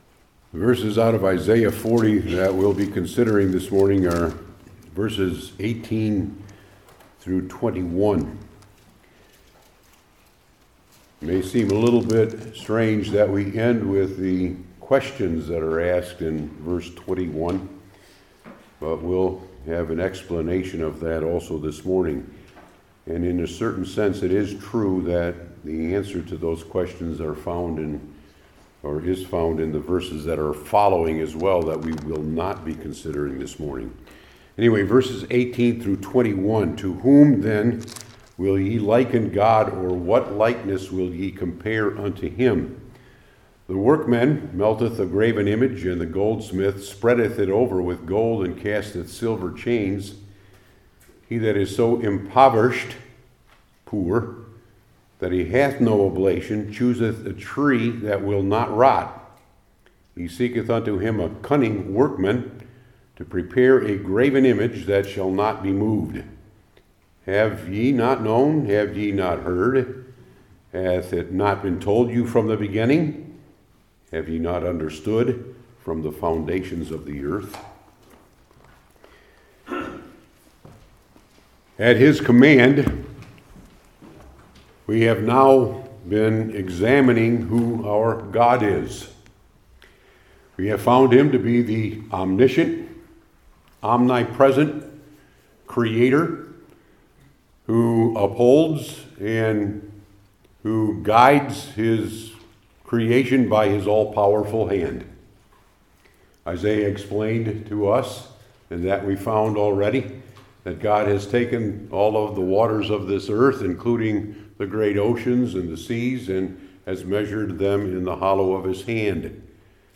Old Testament Sermon Series I. Judah’s Sin II.